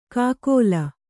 ♪ kākōla